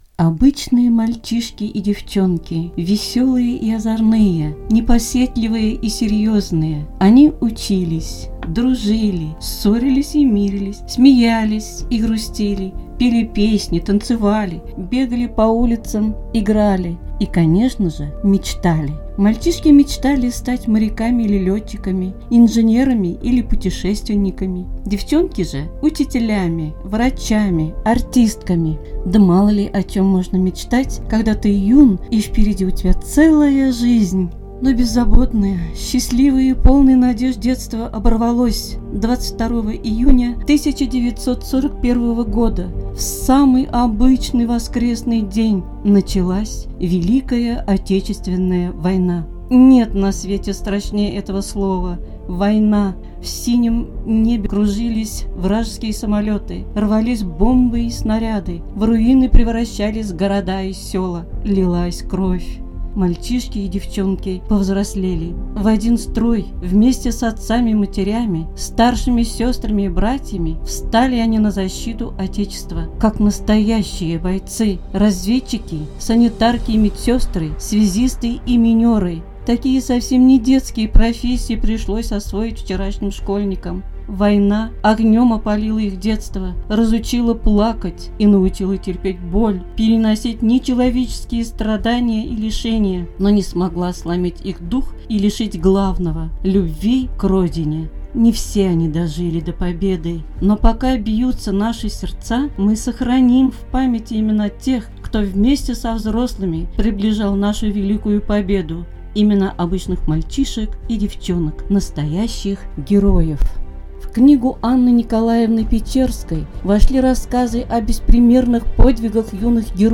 Информ-обзор «Об этом расскажут книги», посвященный юным героям антифашистам